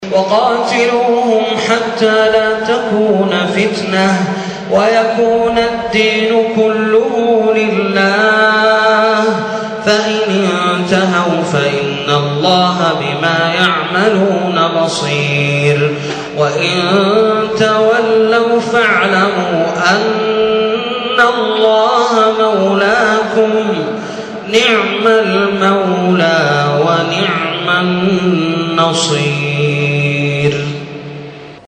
= تسجيل لصلاة التراويح لقارئ بالفايزية يحبه قلبك .. تحداااكم تعرفونه =